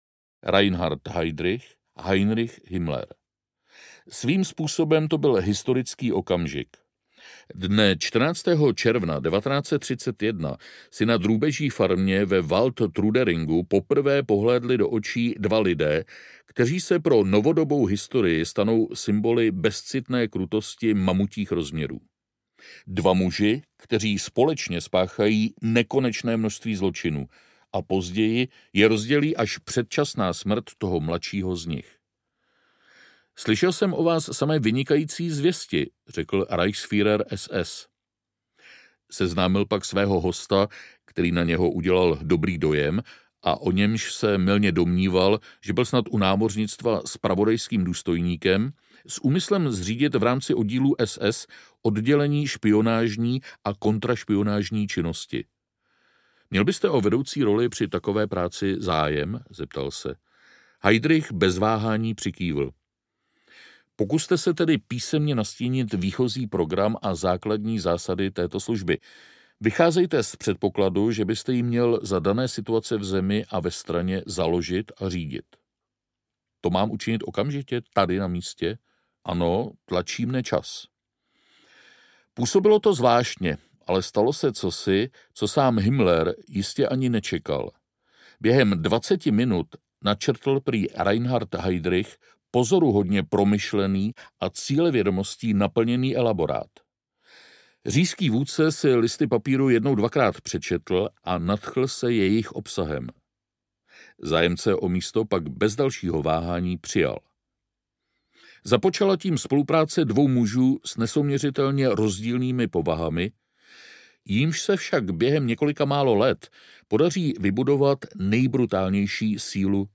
Deset podob zla audiokniha
Ukázka z knihy
Vyrobilo studio Soundguru.